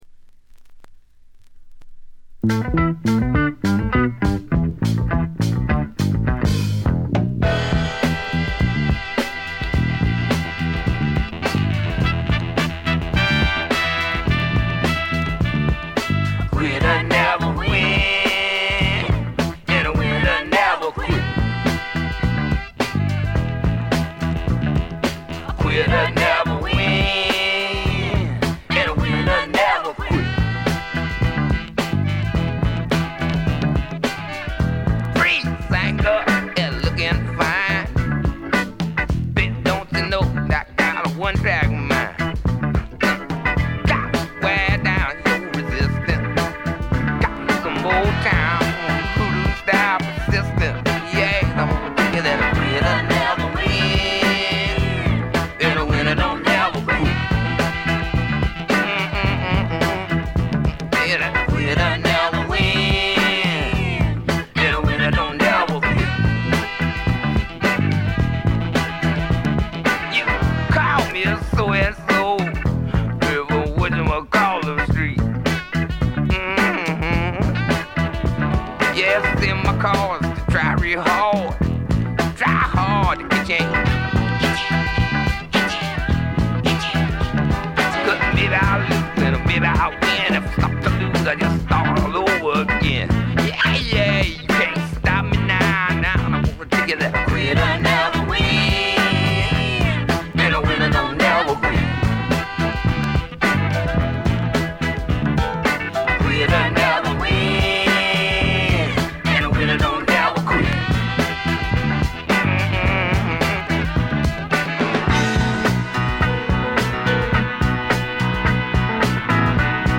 部分試聴ですが、静音部での軽微なチリプチ程度。
試聴曲は現品からの取り込み音源です。
Recorded at Criteria Recording Studios, Miami, Florida.